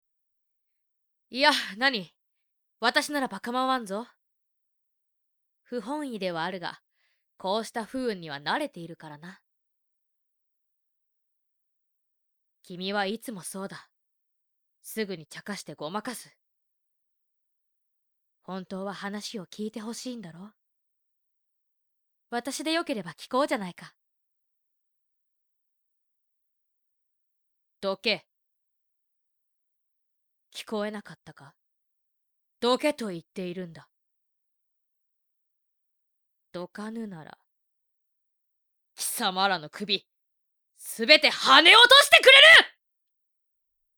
演じてみたよ
性別：女性
紳士のような口調の女性天狗。